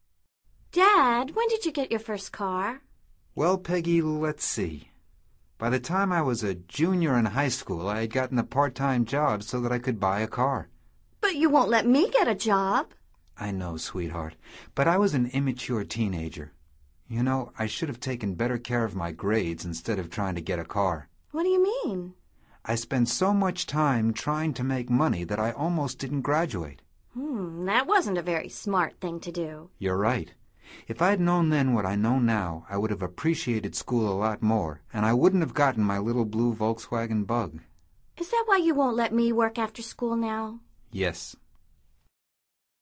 Escucha atentamente esta conversación entre Peggy y su padre y selecciona la respuesta más adecuada de acuerdo con tu comprensión auditiva.